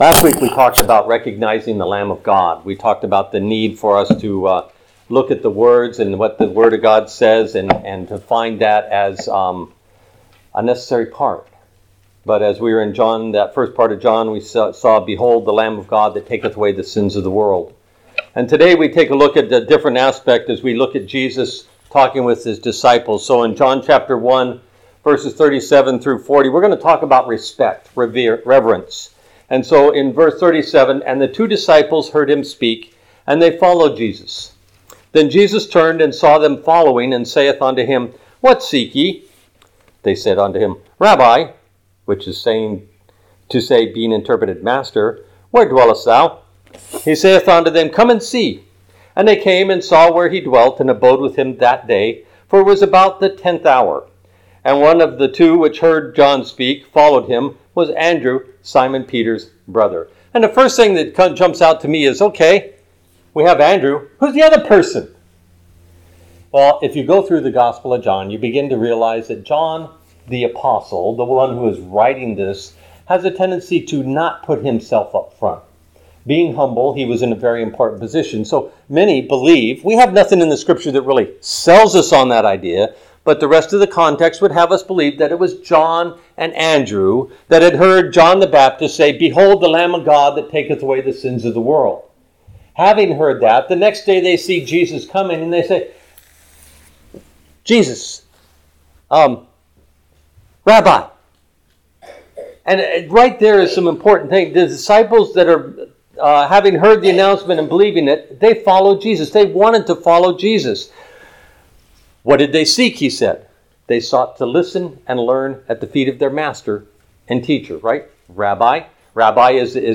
An Expository Sermon on John 1:37-51 Discussing the need to walk and talk with Jesus Everday. Telling others about what we learn through our walk with Jesus.